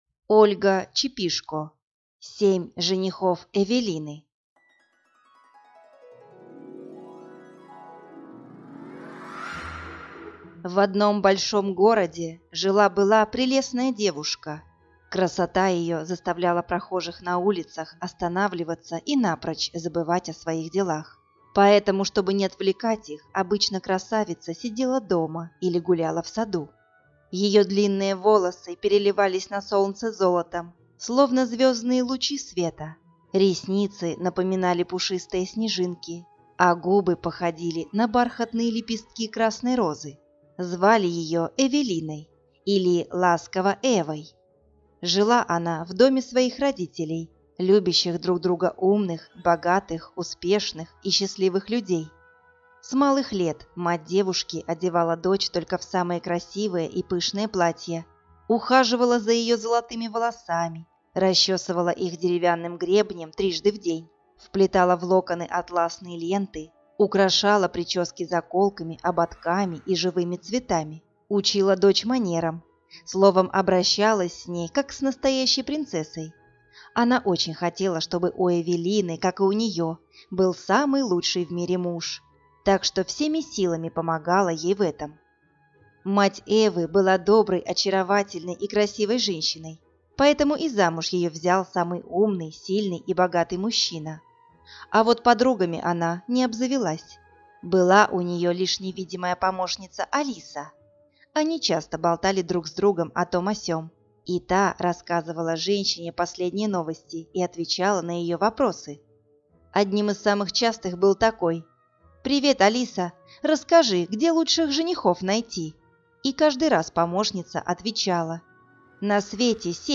Аудиокнига Семь женихов Эвелины | Библиотека аудиокниг